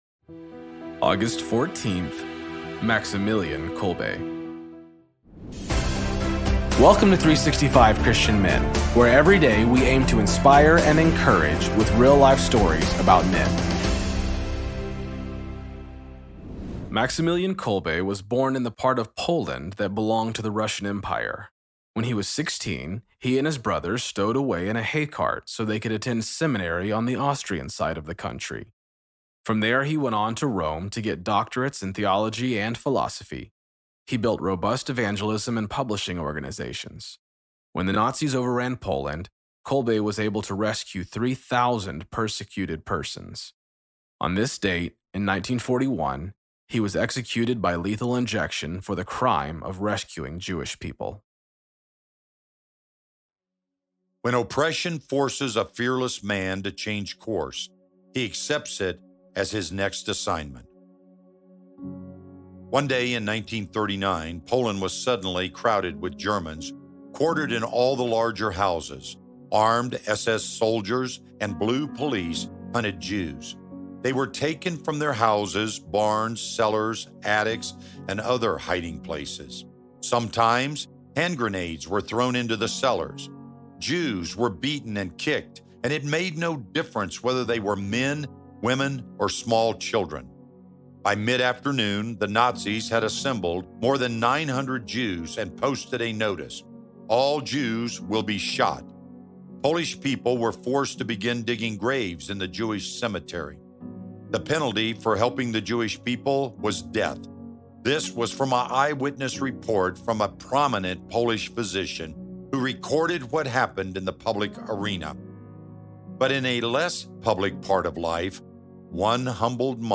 Story read by:
Introduction read by: